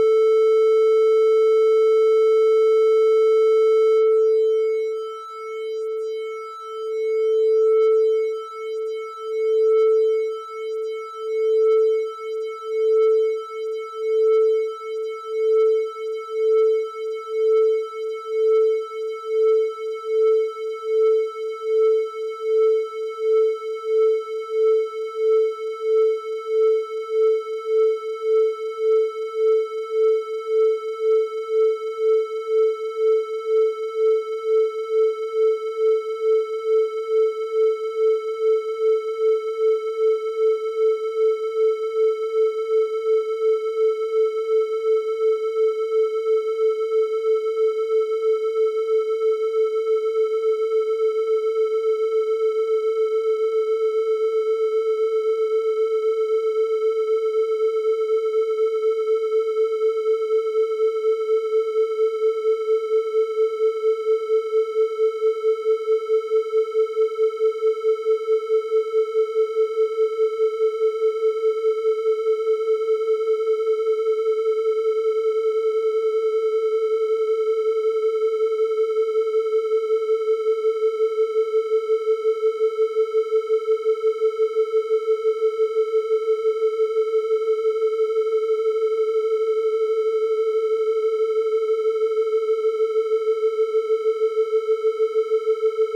Pour comprendre, j'ai simulé le décalage de fréquence à l'aide du logiciel 3xOsc de FL Studio et ça a donné ce 🎧
signal vibrant de type triangulaire triple musette. Toutes les 4 secondes environ, le décalage augmente de 0,01 demi-ton à la hausse et à la baisse jusque 0,25. Quand le décalage est faible, le son pompe. Quand il est grand mais pas trop, il vibre d'une manière beaucoup plus intéressante qu'au tout début où le son était très sec.